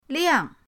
liang4.mp3